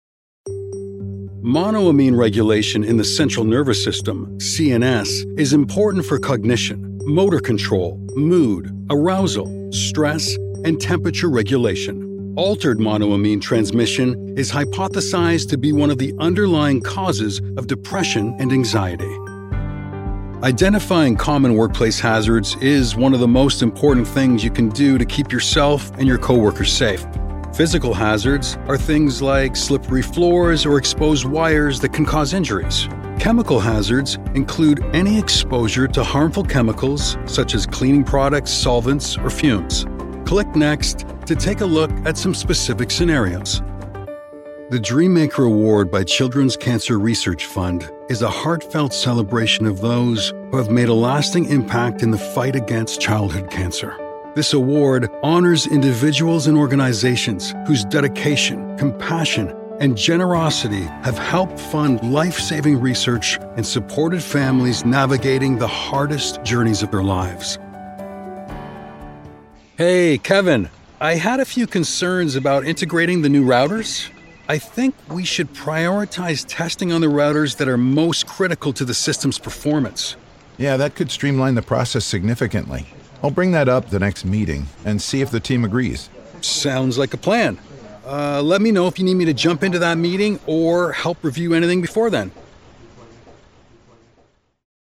Friendly, Warm, Conversational.
eLearning